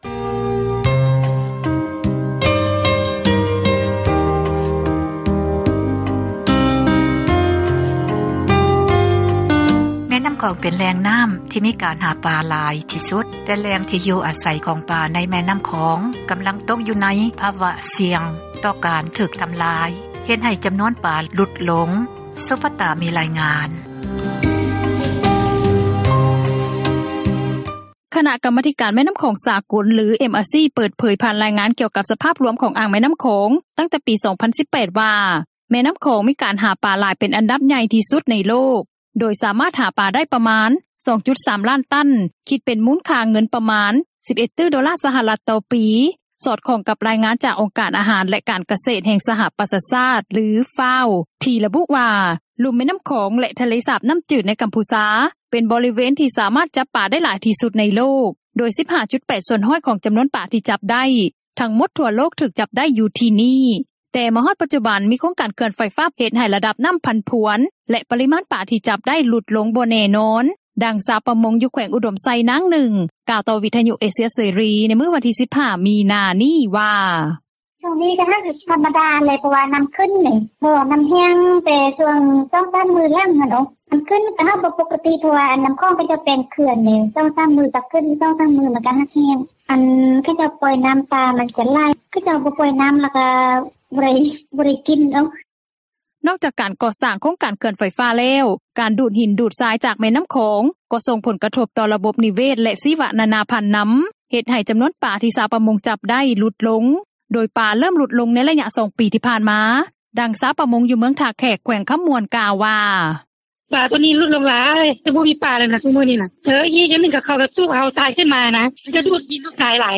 ແຕ່ມາຮອດປັດຈຸບັນ ມີໂຄງການເຂື່ອນໄຟຟ້າ ເຮັດໃຫ້ລະດັບນ້ໍາຜັນຜວນ ແລະປະຣິມານປາທີ່ຈັບໄດ້ຫຼຸດລົງ ບໍ່ແນ່ນອນ, ດັ່ງຊາວປະມົງ ຢູ່ແຂວງອຸດົມໄຊ ນາງນຶ່ງ ກ່າວຕໍ່ວິທຍຸເອເຊັຽເສຣີ ໃນມື້ວັນທີ 15 ມິນາ ນີ້ວ່າ:
ເຊິ່ງສາເຫດ ທັງ 3 ນີ້ ພົວພັນກັນ ແລະສົ່ງຜົລກະທົບເຊິ່ງກັນແລະກັນ, ດັ່ງນັກອະນຸຮັກດ້ານການປະມົງ ຊາວລາວ ຜູ້ນຶ່ງກ່າວວ່າ: